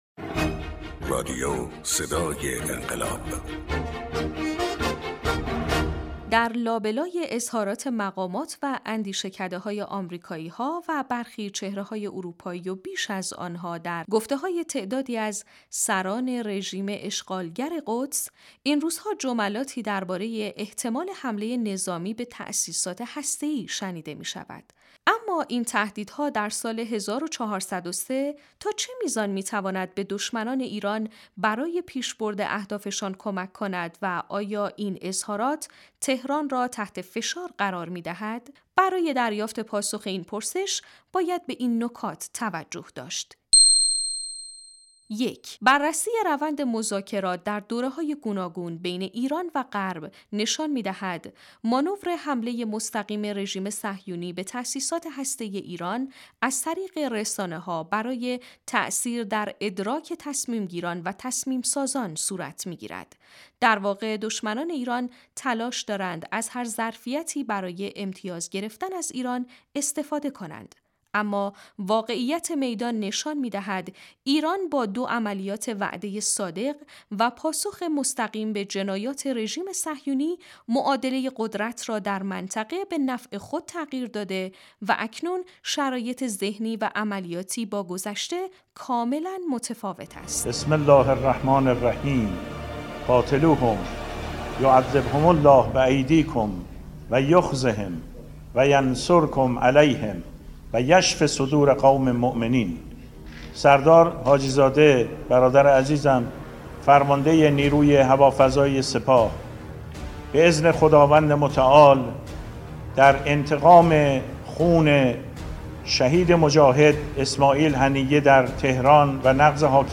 برچسب ها: بصیرت ، صدای انقلاب ، گزارش روز ، سران رژیم اشغالگر قدس ، حمله نظامی به تأسیسات هسته‌ای ایران